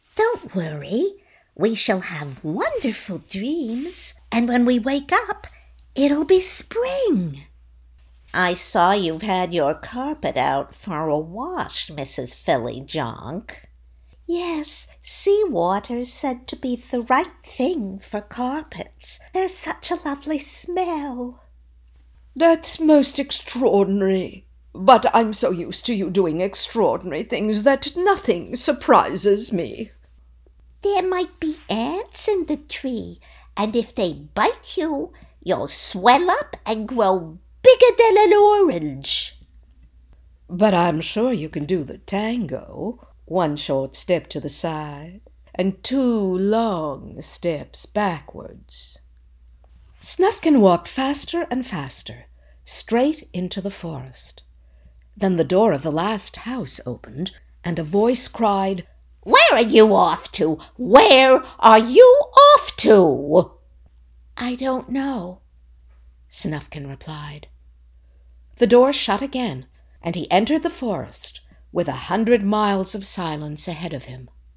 Animation - ANG